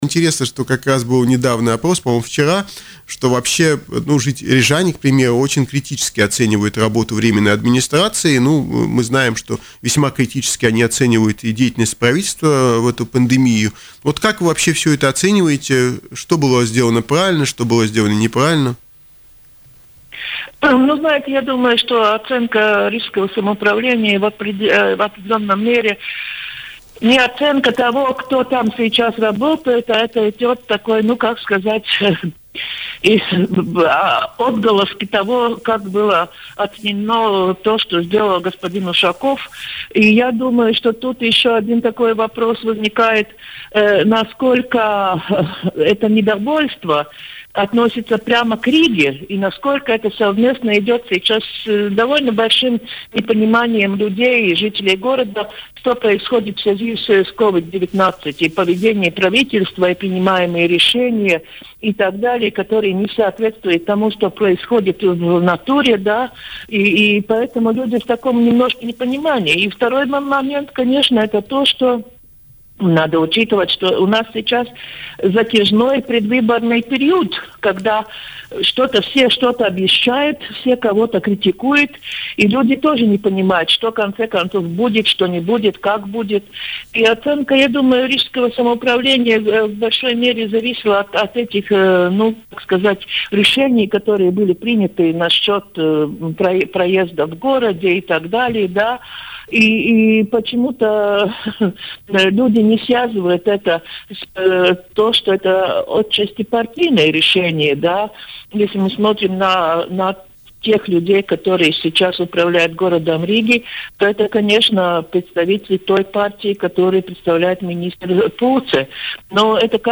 Об этом в эфире радио Baltkom заявила политолог, профессор университета им. Страдиня, экс-спикер Сейма Илга Крейтусе.